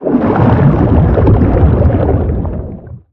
Sfx_creature_shadowleviathan_swimpatrol_06.ogg